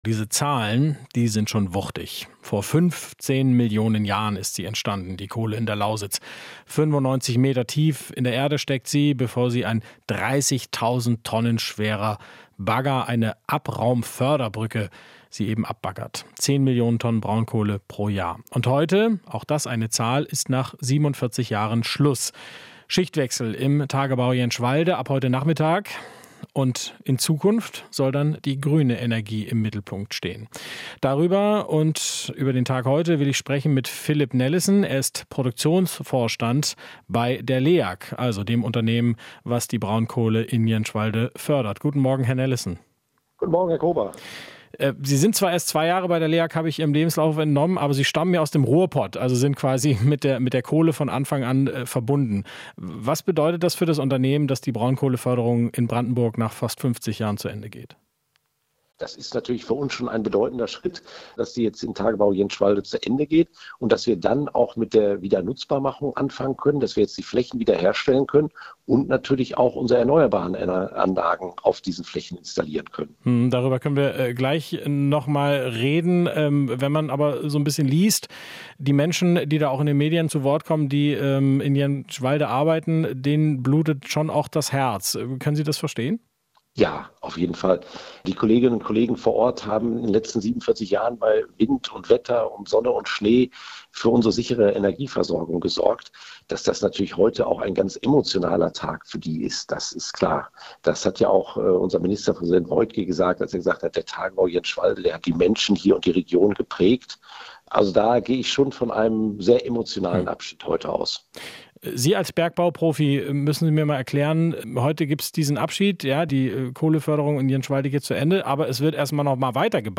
Interview - Leag-Vorstand: Emotionaler Tag für Bergleute in Jänschwalde